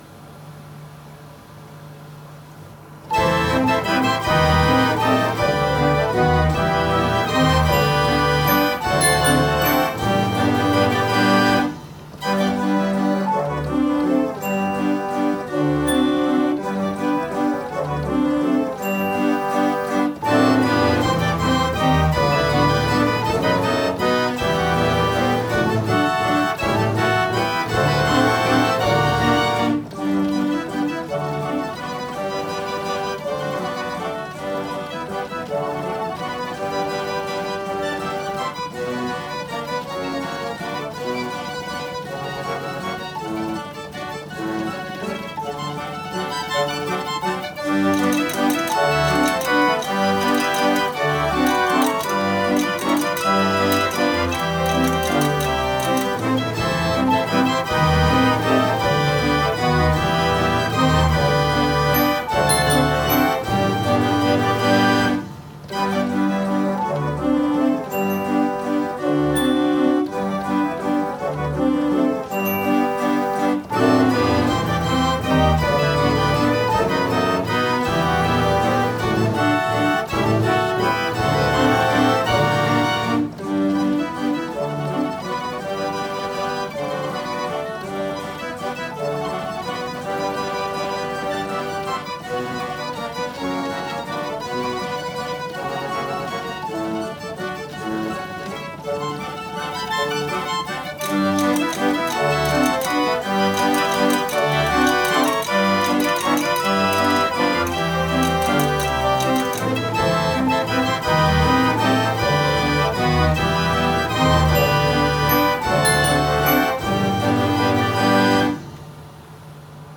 New Zealand Photoplayer Restoration Progress